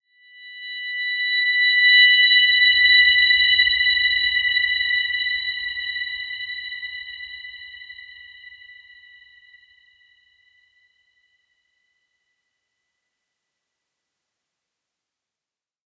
Dreamy-Fifths-B6-mf.wav